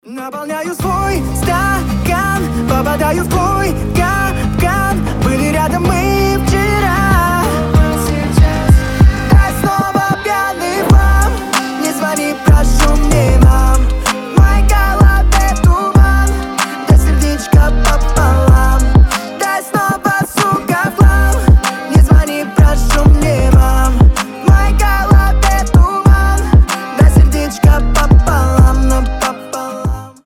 • Качество: 320, Stereo
молодежные